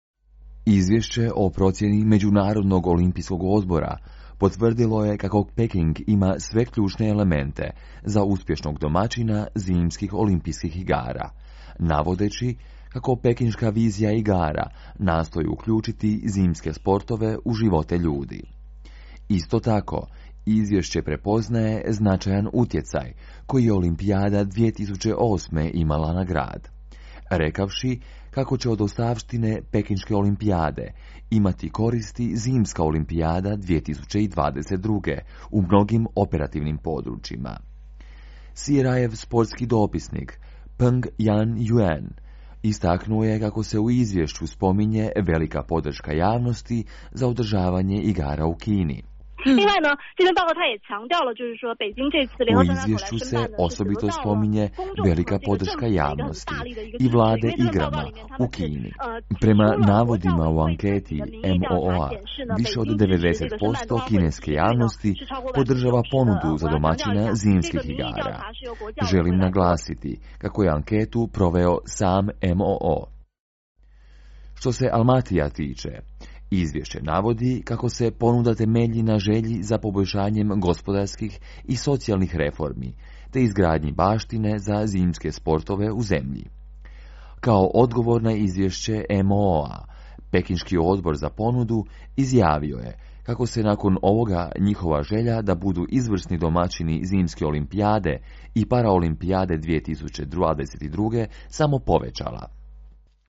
female/Mandarin